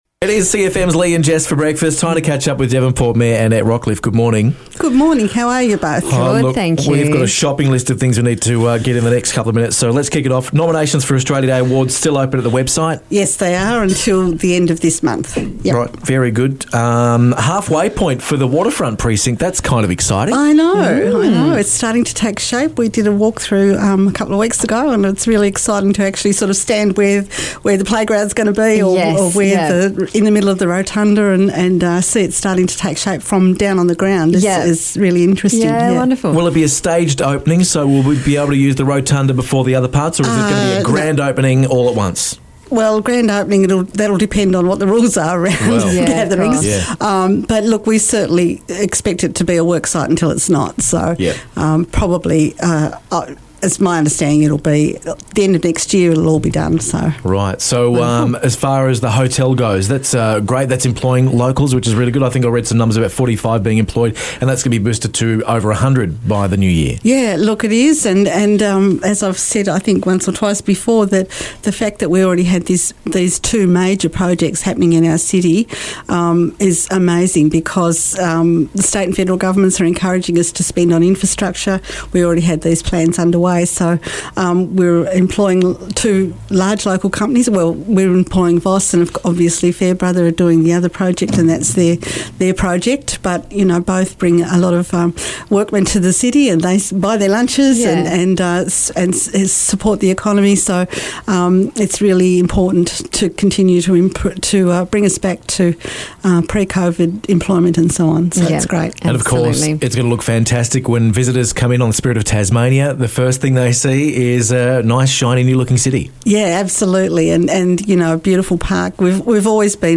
Annette Rockliff stopped by for a chat about work happening around Devonport's waterfront and things to look forward to in 2021.